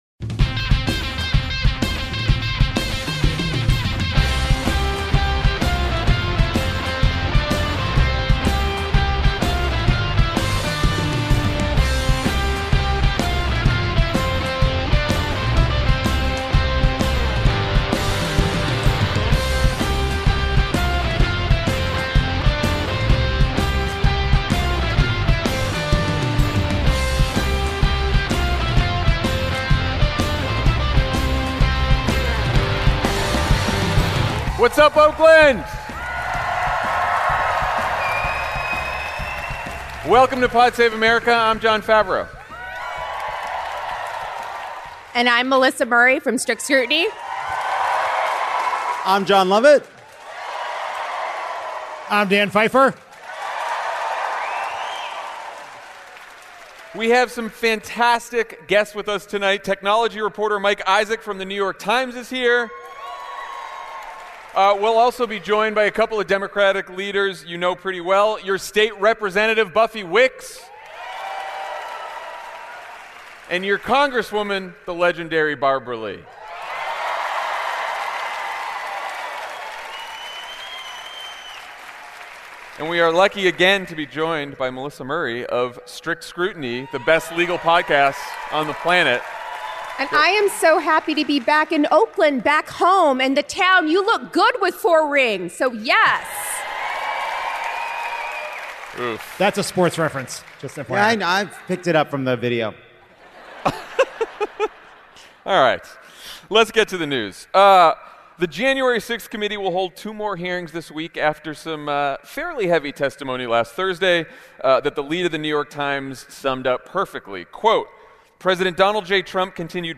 Live from Oakland!